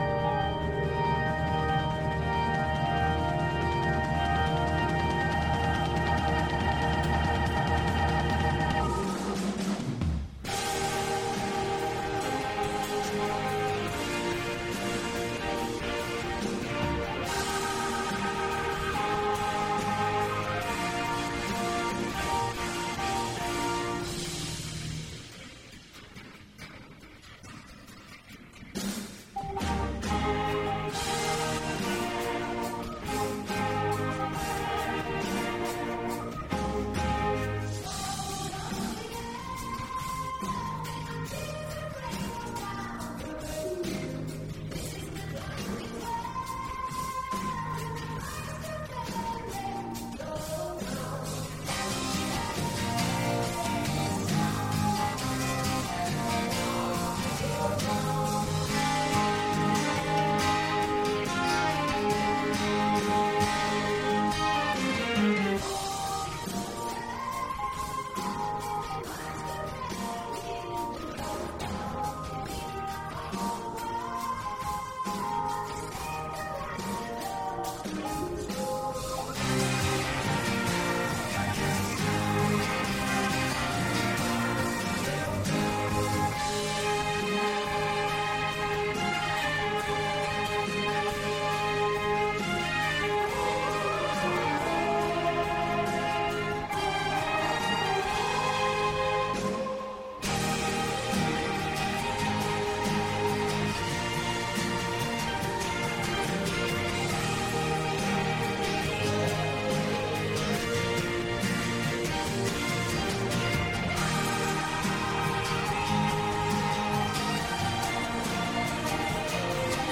Un’oretta di musica varia
mix di afrobeat, ethiojazz e psychfunk